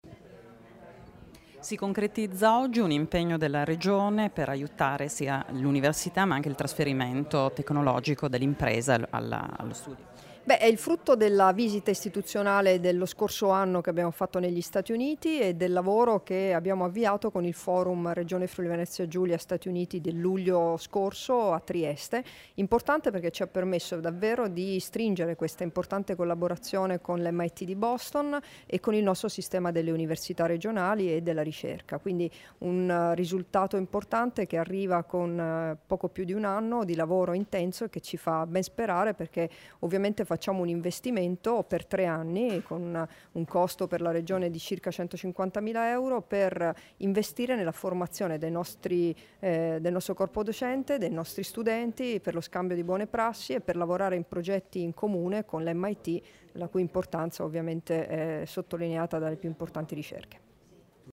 Dichiarazioni di Debora Serracchiani (Formato MP3)
a margine della firma dell'accordo di cooperazione tra Regione, sistema universitario del FVG e Massachusetts institute of technology (Mit), rilasciate a Udine il 23 giugno 2017